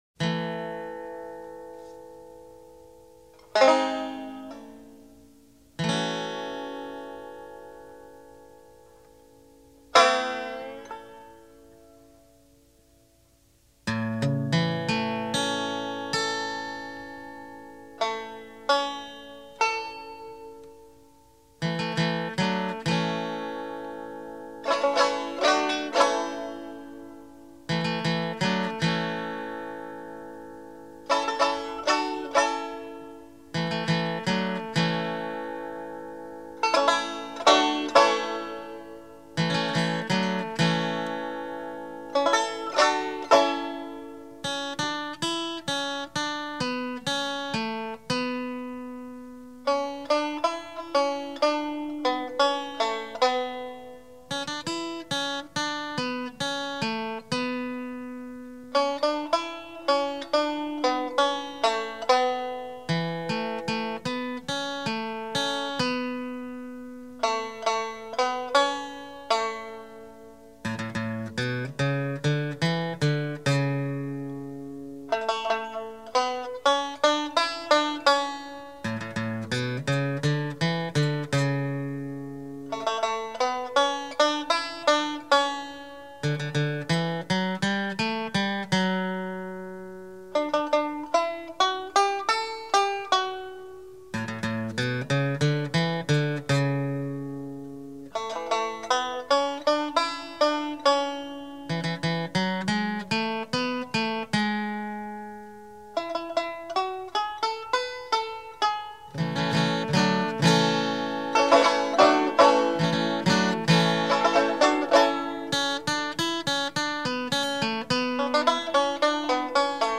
Banjo+Guitar
Banjo_Guitar.mp3